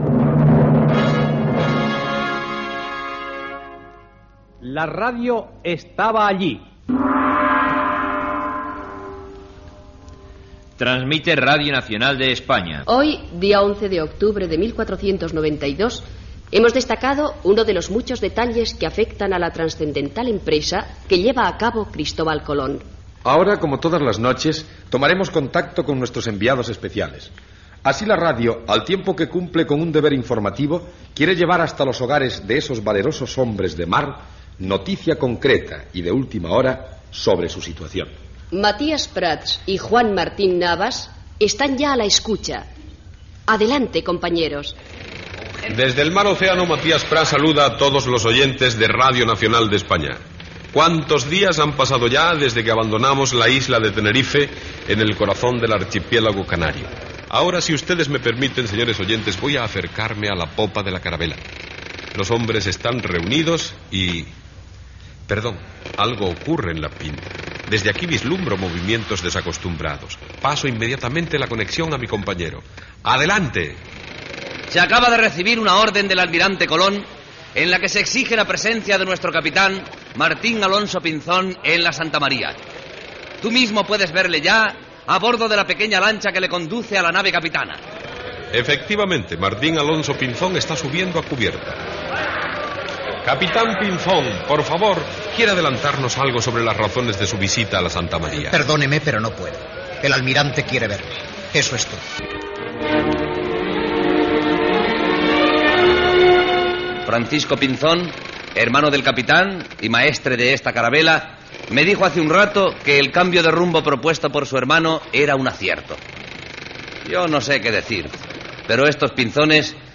Careta del programa, identificació, data i connexió amb les caravel·les de l'almirall Colom pocs instants abans d'arribar al continent Americà.
Ficció